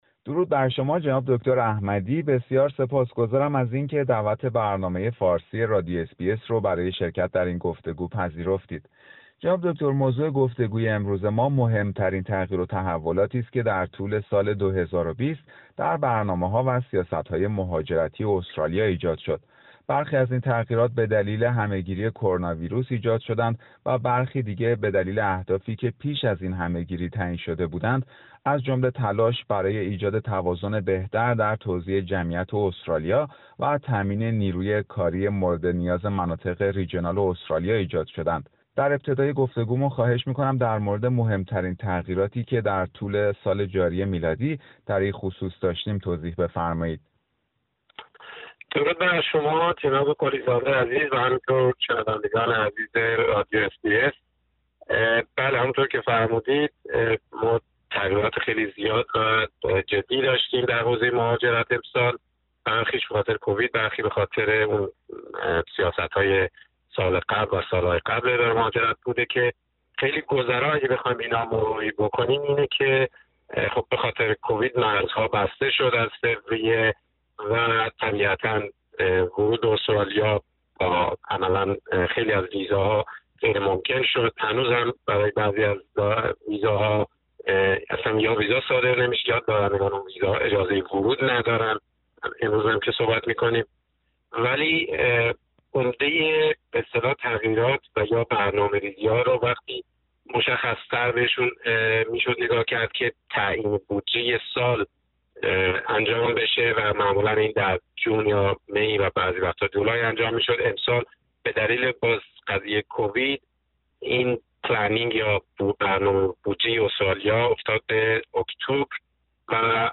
گفتگویی در مورد برخی از مهمترین تغییر و تحولات در برنامه مهاجرتی استرالیا در سال ۲۰۲۰